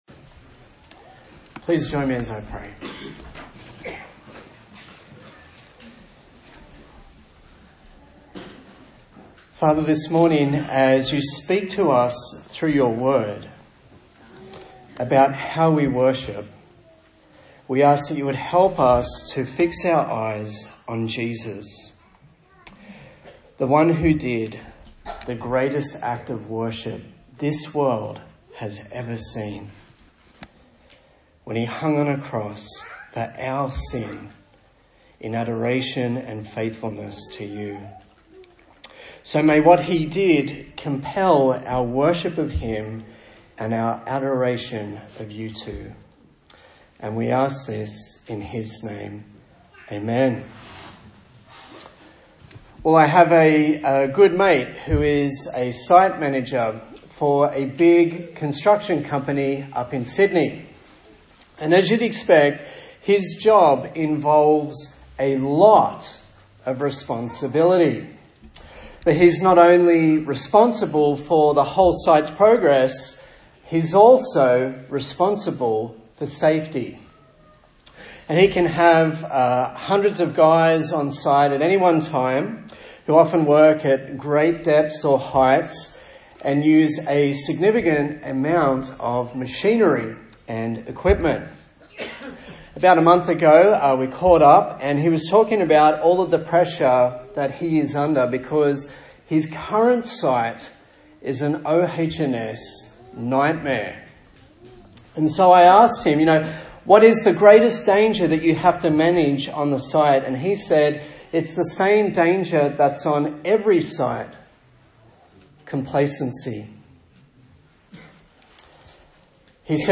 Service Type: Sunday Morning A sermon in the series on the book of Ecclesiastes